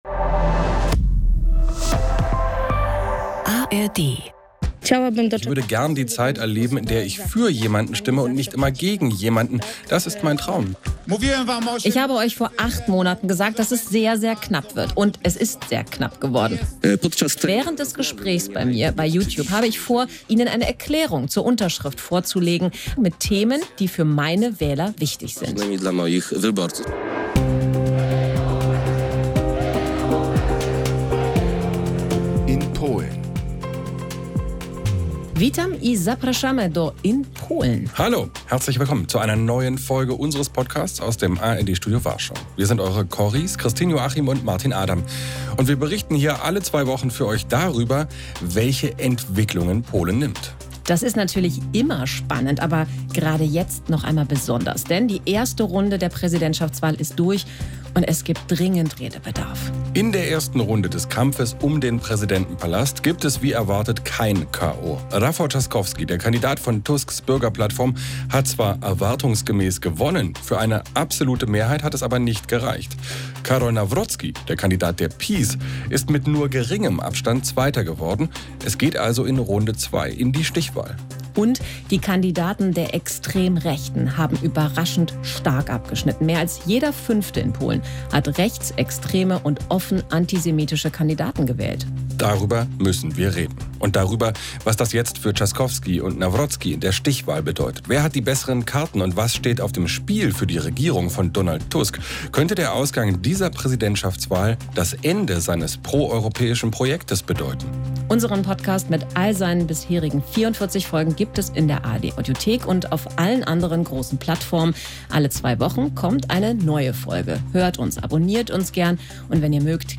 "In Polen" ist der Podcast aus dem ARD-Studio in Warschau